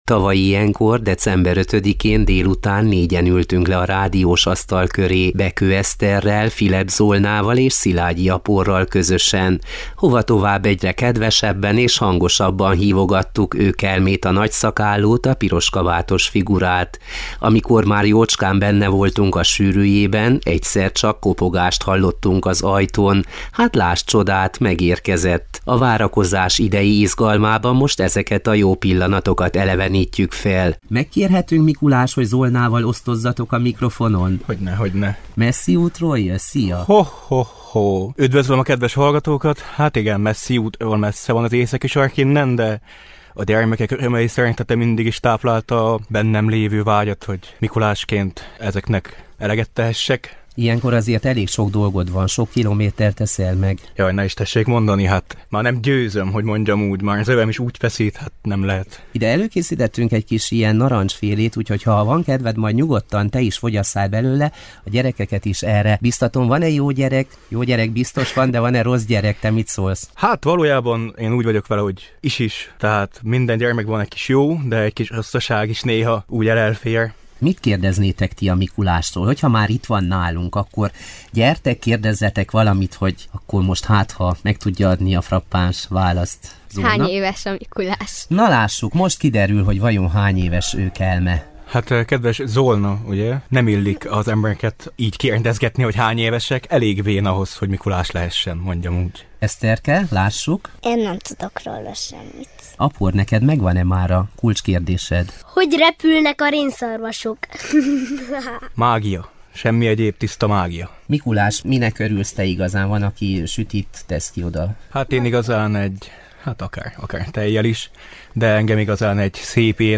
Egyre kedvesebben és hangosabban hívogatták őkelmét, és amikor már jócskán benne voltak a sűrűjében, egyszercsak kopogást hallottak az ajtón!